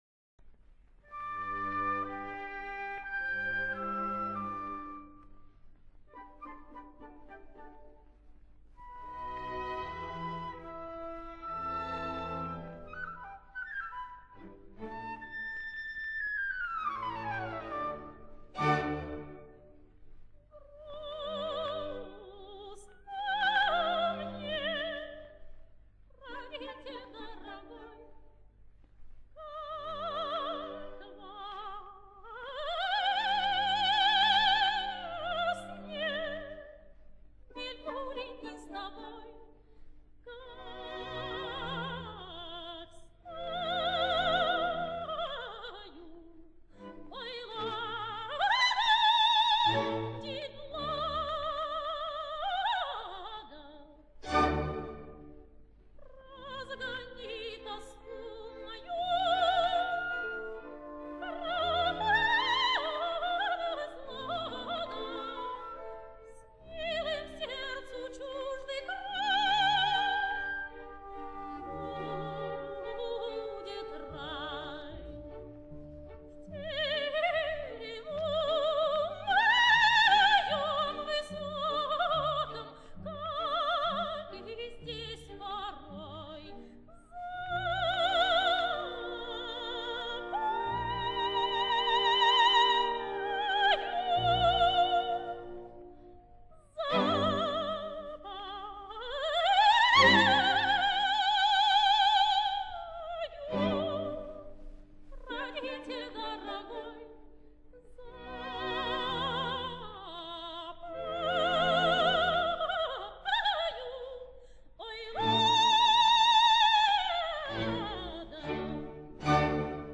Обладала лирико-колоратурным сопрано красивого серебристого тембра, подвижной вокальной техникой, свободным верхним регистром, тонкой нюансировкой; исполнение отличалось простотой и естественностью.
Опера «Руслан и Людмила». Каватина Людмилы. Хор и оркестр Большого театра. Дирижёр К. П. Кондрашин. Исполняет В. М. Фирсова.